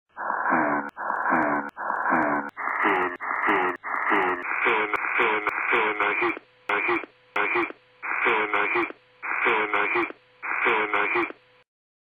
Here is one more version- I split the 4 sylables. The first two sylables are repeated and brought up to regular speed, then the last two sylables are repeated. Finally the whole phrase is played as one. The equalization was not touched.)